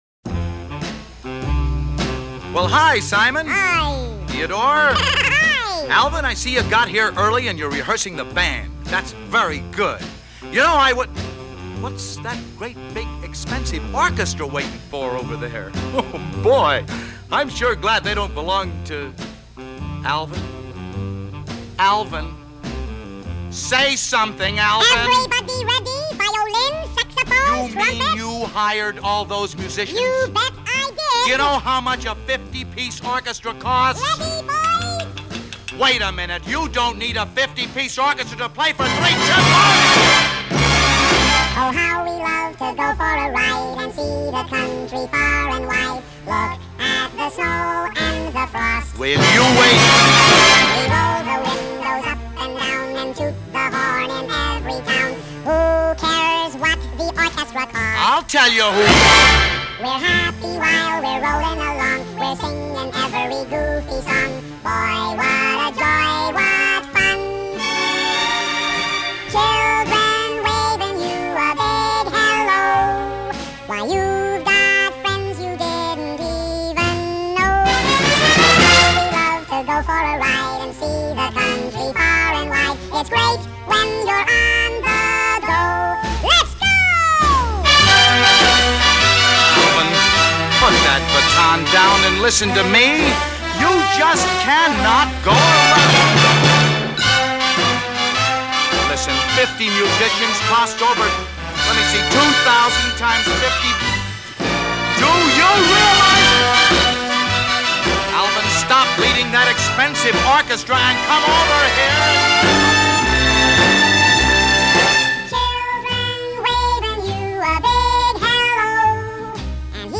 The production values are absolutely flawless.